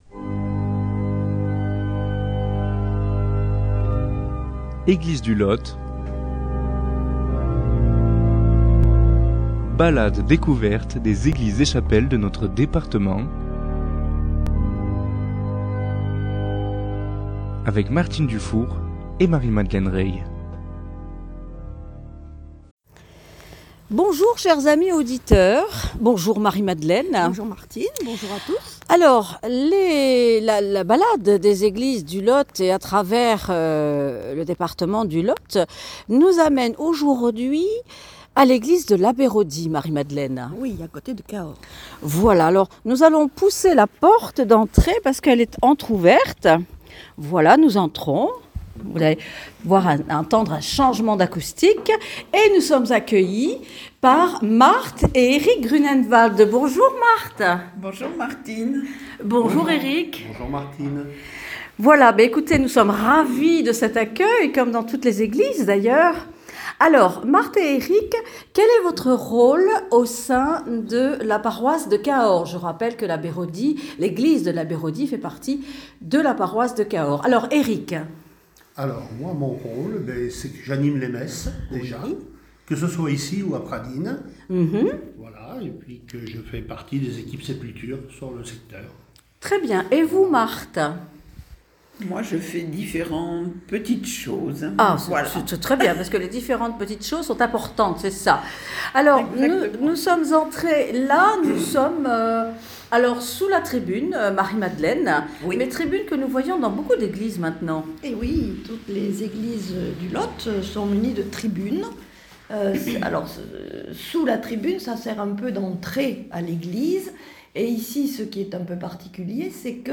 Aujourd’hui, nous sommes à Labéraudie, paroisse de Cahors, pour la visite de l’église Sainte Croix.